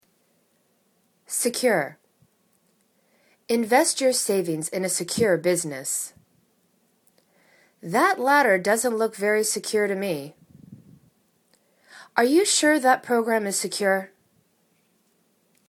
se.cure /si'kyoor/ adj